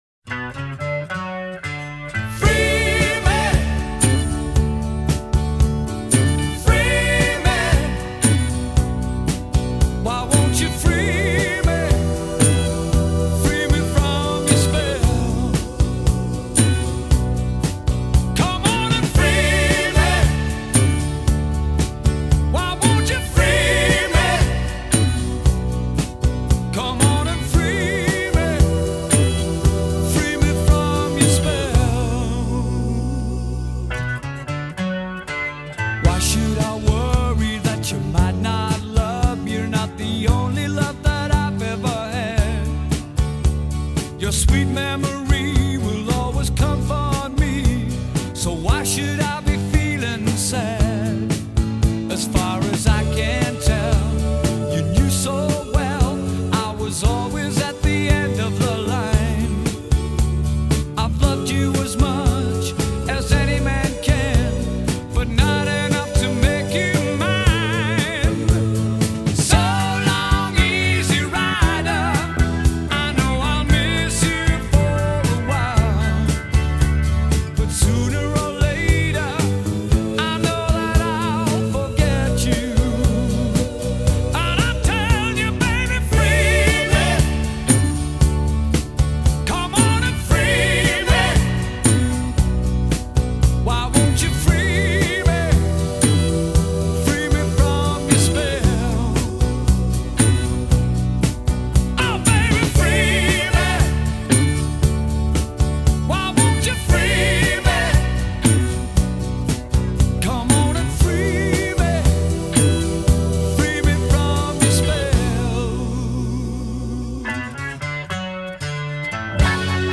cafetero